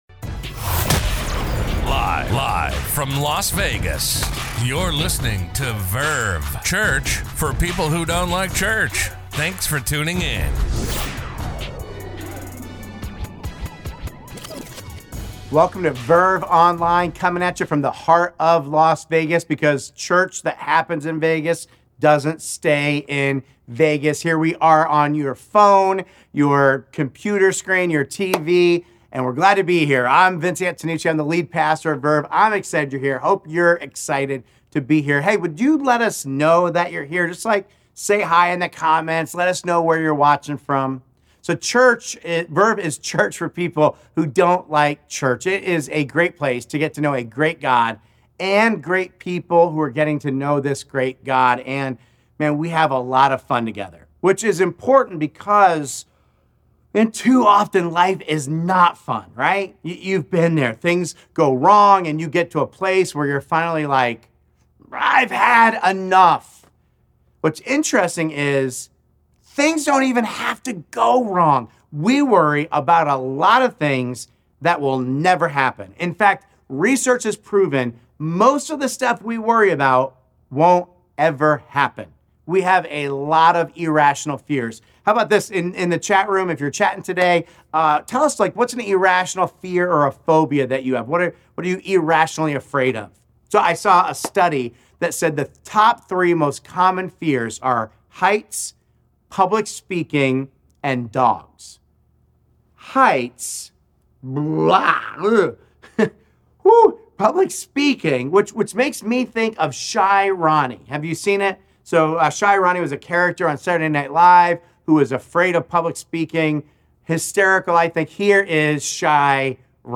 A message from the series "Anxious For Nothing."